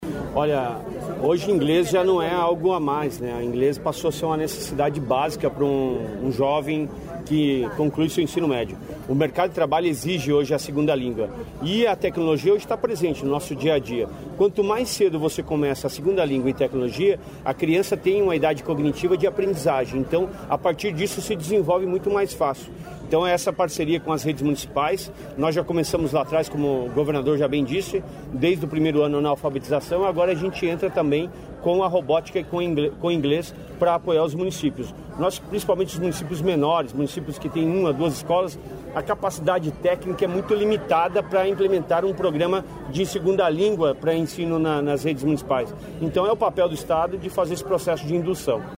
Sonora do secretário da Educação, Roni Miranda, sobre a implementação de inglês e robótica no Ensino Fundamental